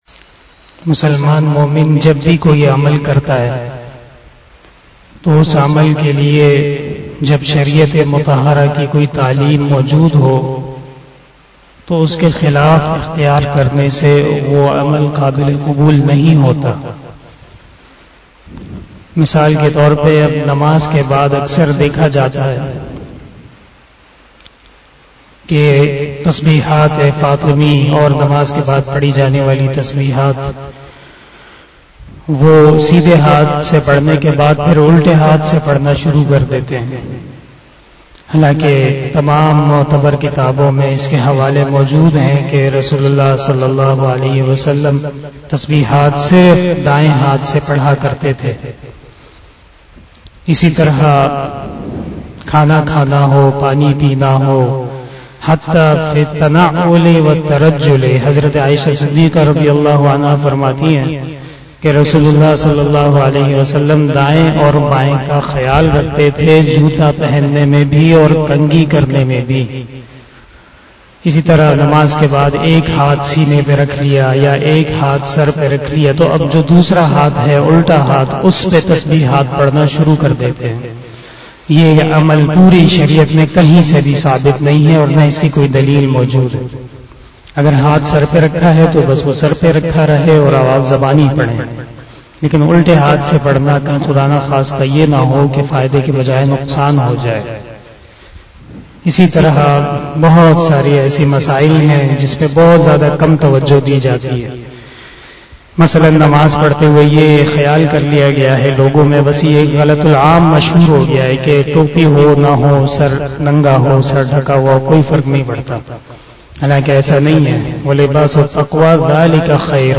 After Asar Namaz Bayan